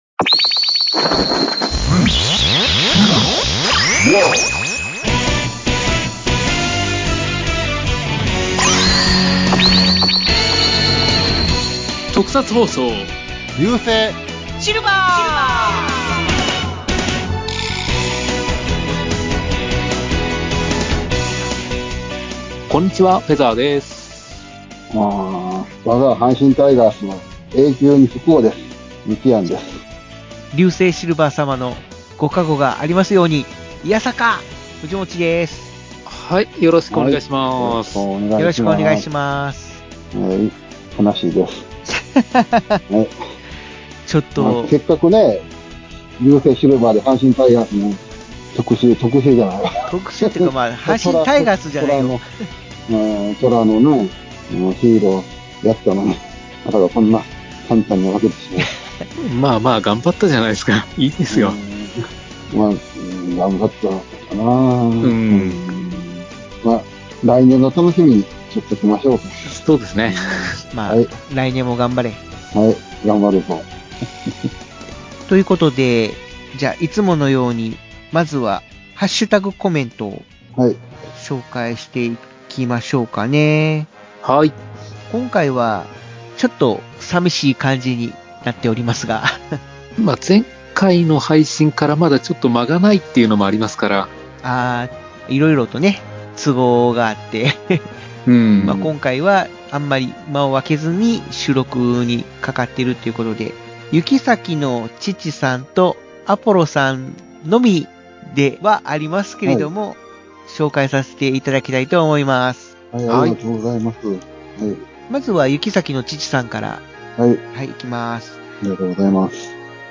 毎回、様々な特撮にまつわるテーマを元に、３人がじっくりコトコトおたくトークを繰り広げるポッドキャストです♪ 今回は「トラがモチーフのヒーロー」について熱く語っています！